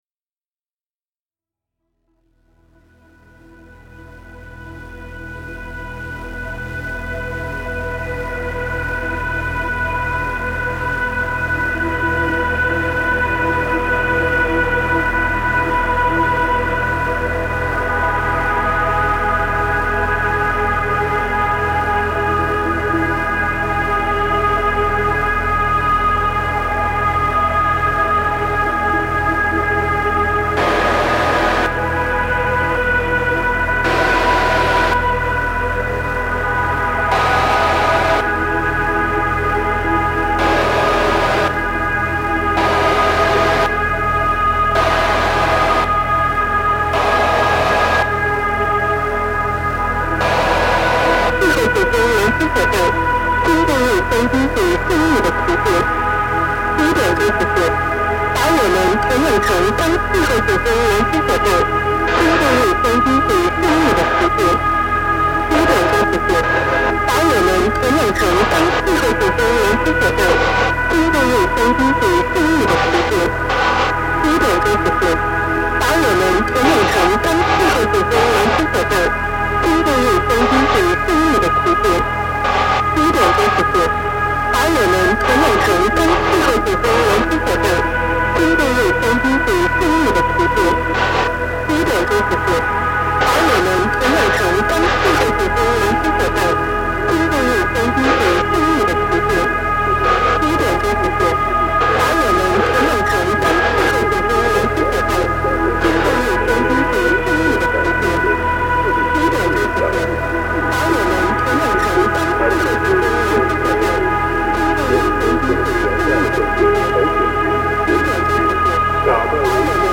documenting and reimagining the sounds of shortwave radio